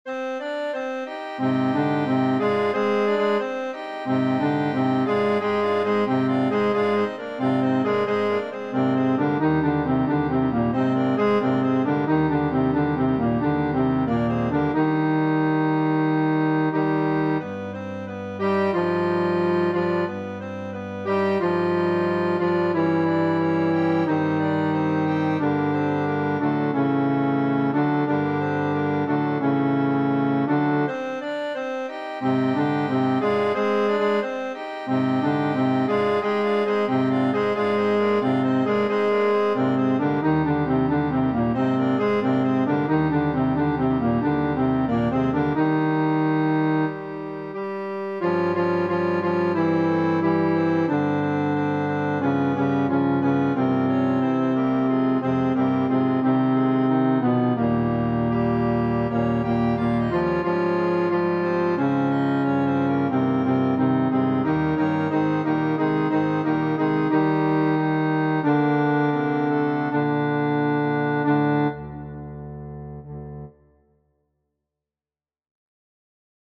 FF:HV_15b Collegium male choir
Na_prievoze-Bar.mp3